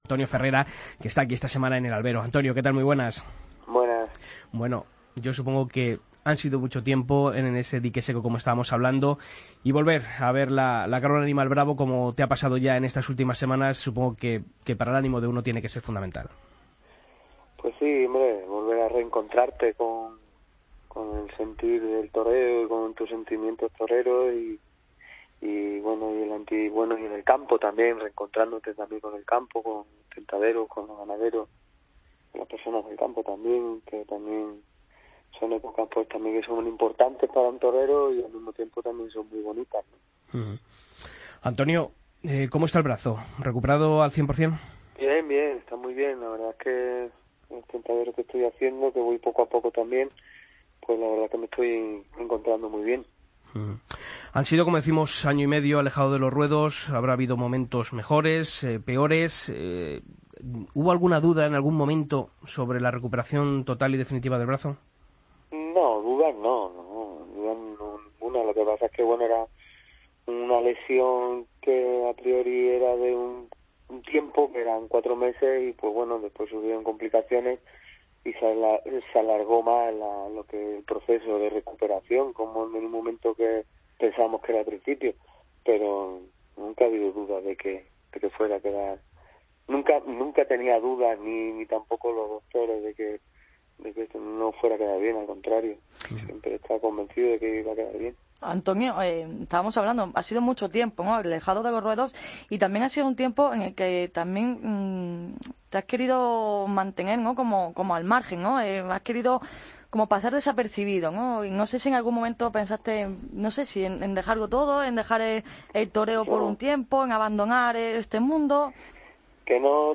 Escucha la entrevista a Antonio Ferrera en El Albero